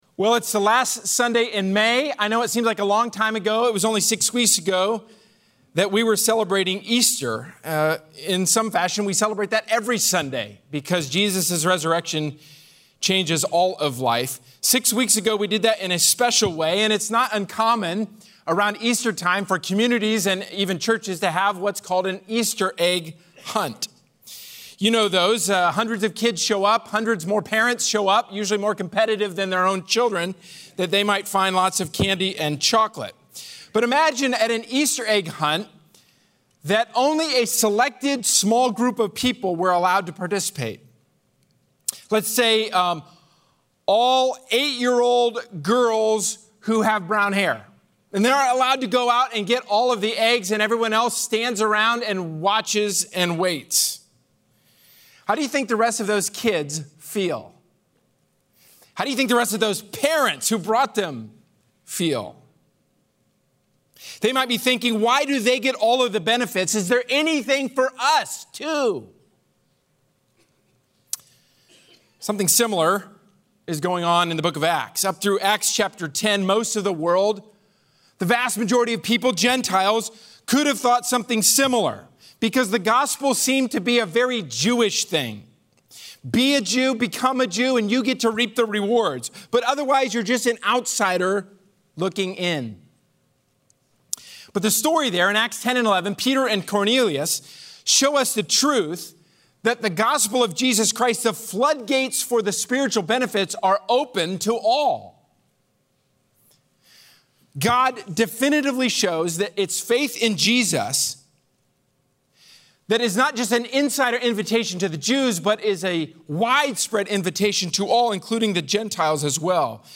A sermon from the series "Can I Get a Witness."